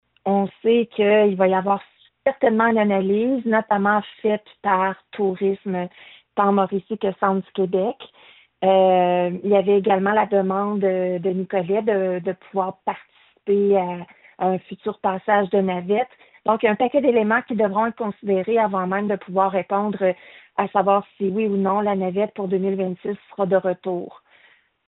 La mairesse actuelle de Bécancour, Lucie Allard, a apporté quelques précisions.